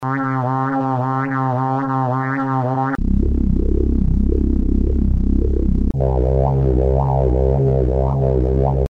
Hear LFO modulator
digeridoo.mp3